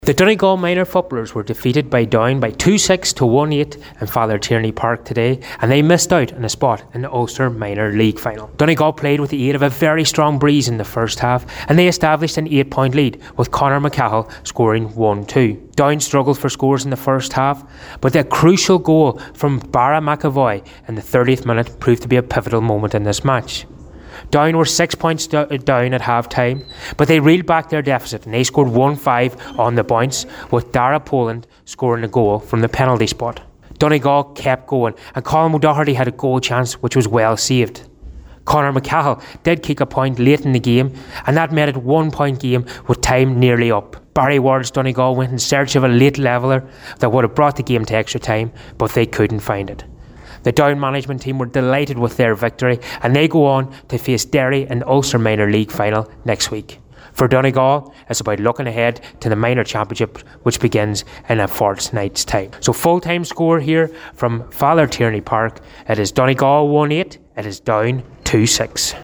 full-time-report-edited-minors-sat.mp3